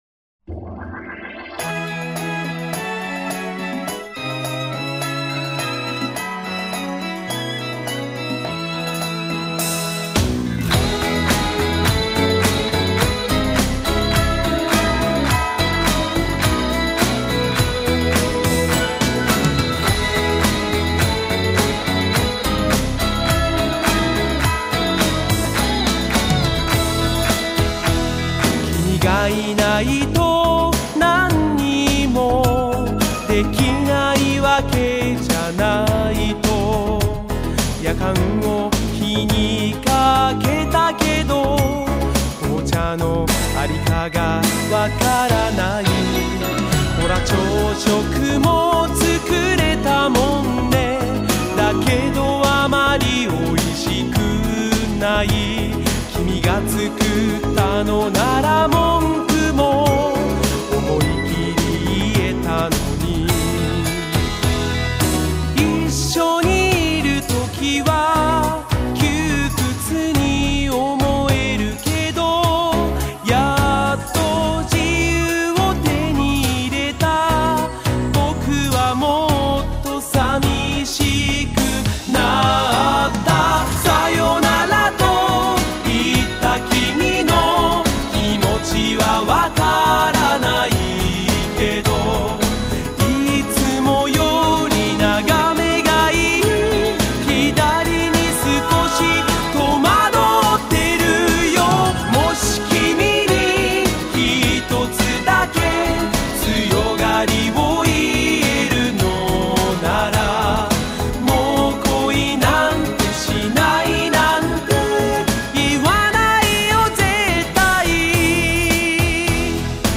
用欢快的旋律唱出那么伤感的歌词